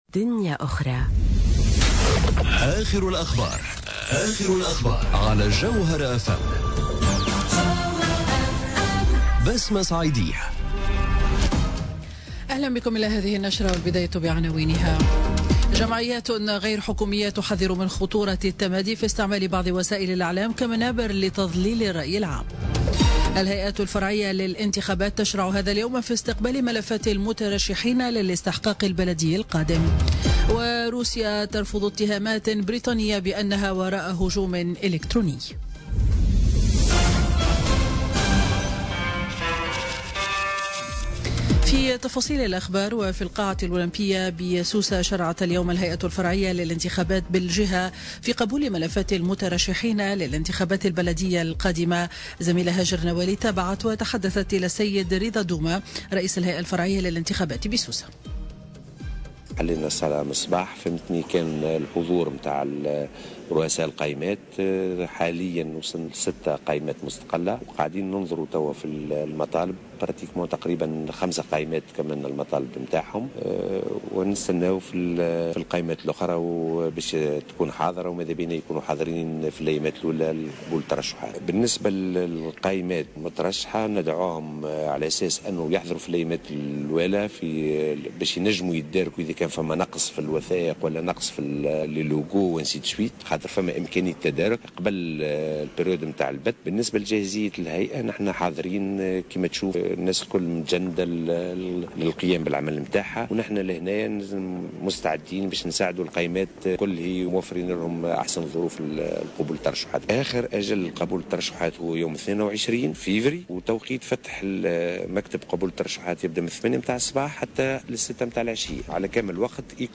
نشرة أخبار منتصف النهار ليوم الخميس 15 فيفري 2018